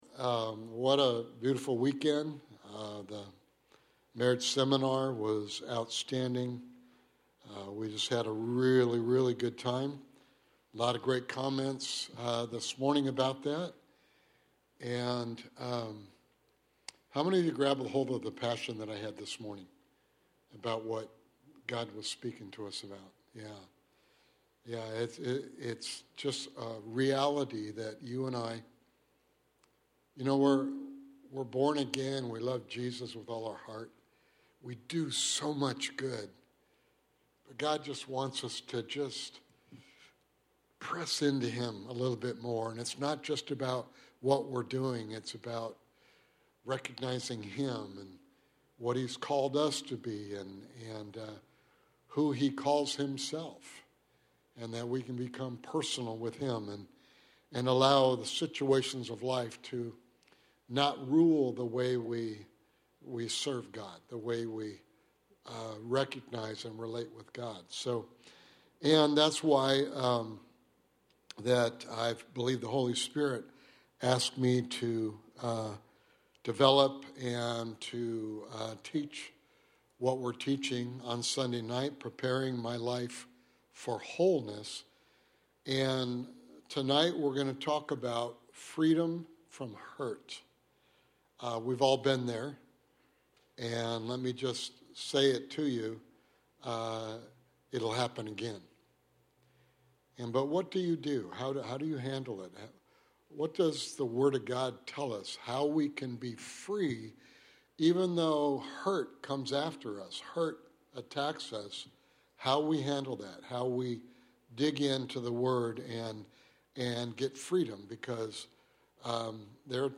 Sunday evening Bible study